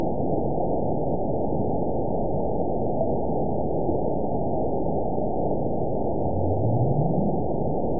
event 920883 date 04/13/24 time 04:49:25 GMT (1 year, 2 months ago) score 9.62 location TSS-AB02 detected by nrw target species NRW annotations +NRW Spectrogram: Frequency (kHz) vs. Time (s) audio not available .wav